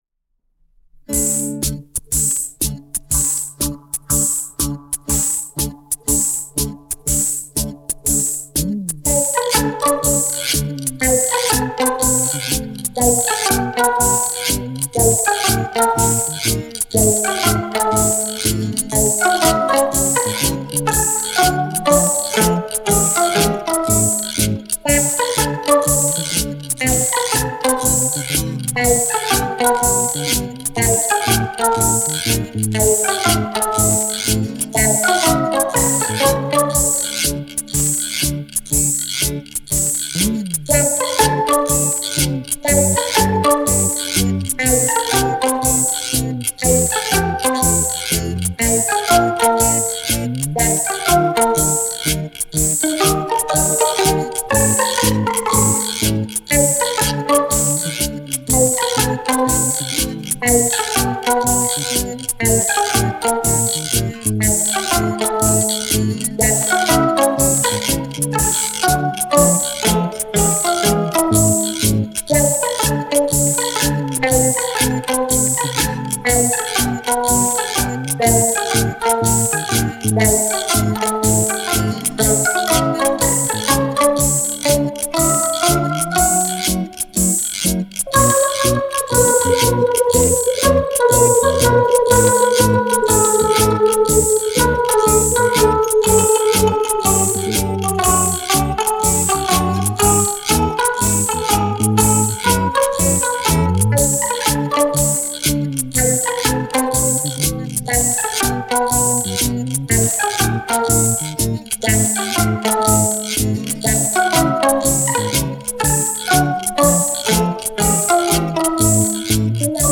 músicas engraçadinhas com drum machine